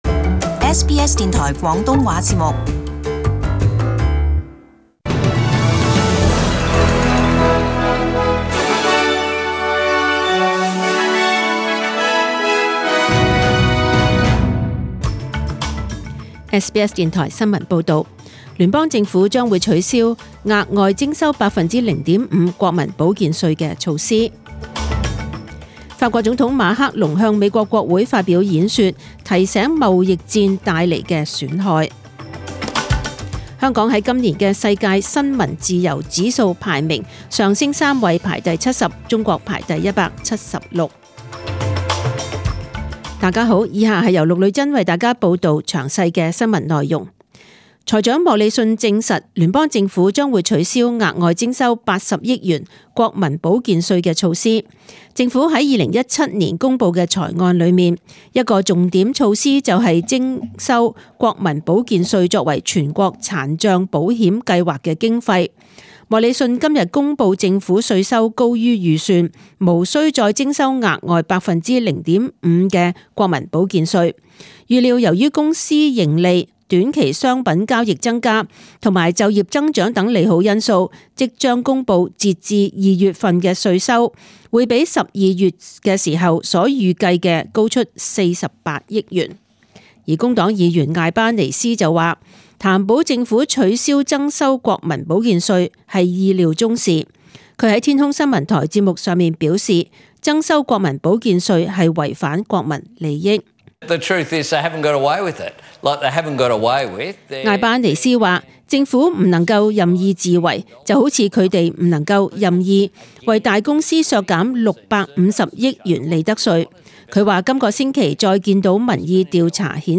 SBS中文新闻 （四月二十六日）
请收听本台为大家准备的详尽早晨新闻。